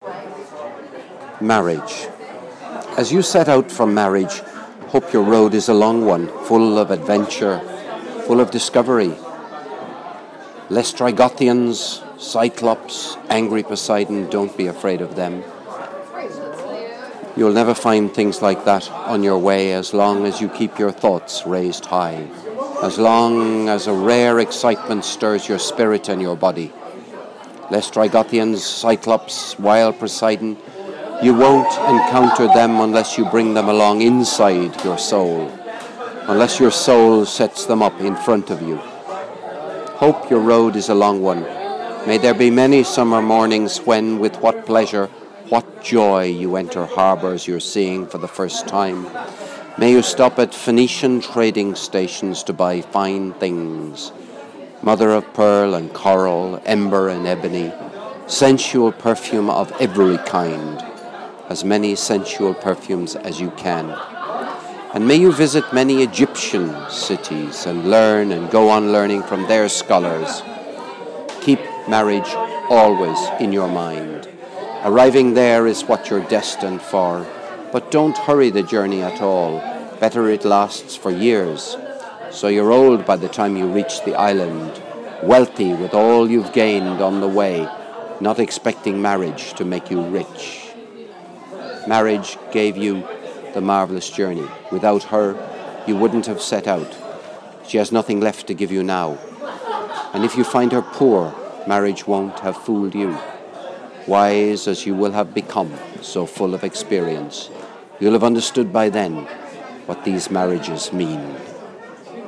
Wish I'd practised the pronunciation of "Laistrygonians" - I might have got it right in the end.
This is one of the two poems which I read as part of the father of the groom speech at the wedding celebration: "Ithaka" This recording was a rehearsal - to see how long it would take to read it live to the gathering.